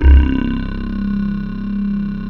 M12-LOW C2-R.wav